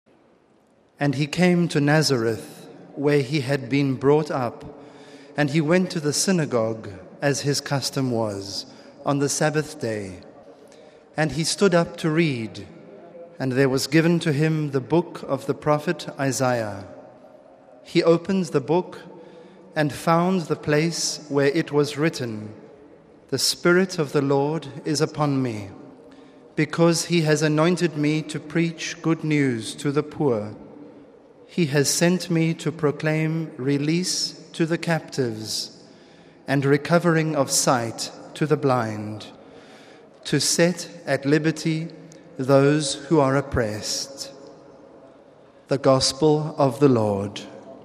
The general audience of Jan. 29 was held in the open, in Rome’s St. Peter’s Square. It began with aides reading a passage from the Gospel of St. Luke, in several languages.